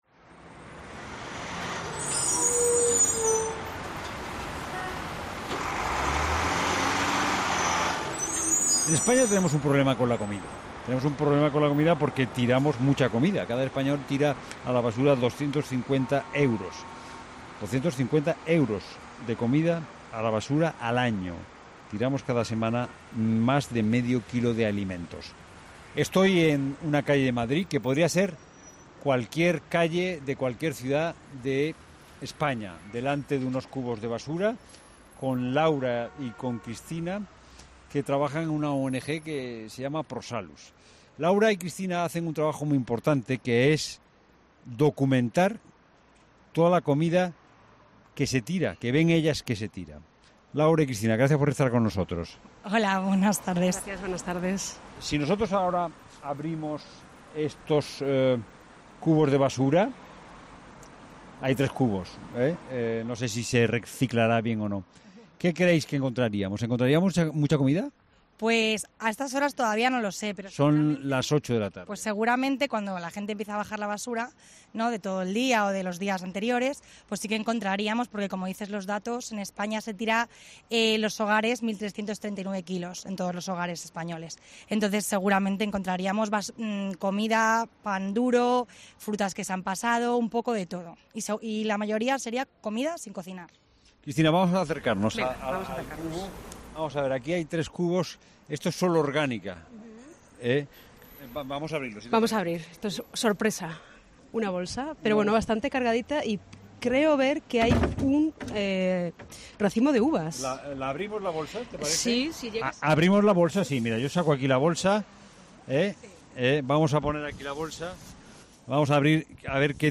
La Tarde de COPE se ha ido a la calle a comprobar cuánta comida tiramos a la basura
La Tarde sale a la calle para comprobar la comida que se tira a la basura